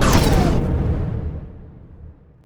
youhit2.wav